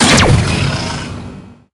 robotshoot_01.ogg